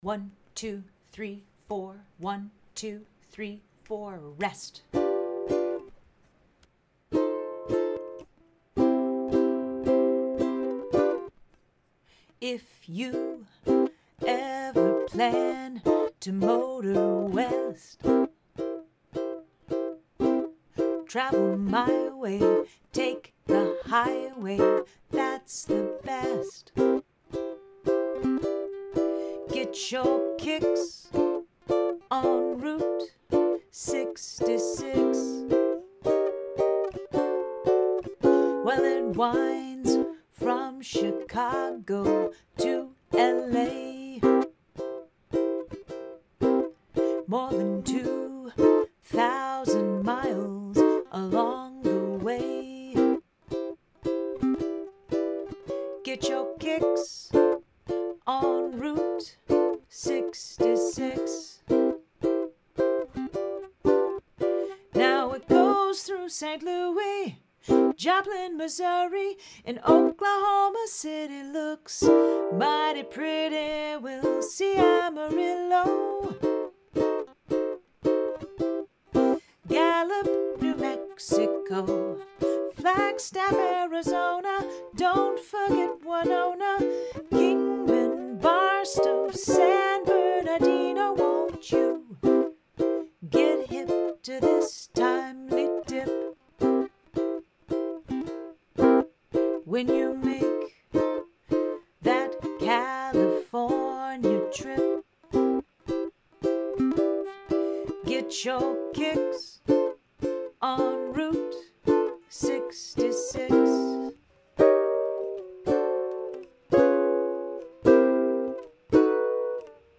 Play Along Track: Strums and Lead Vocal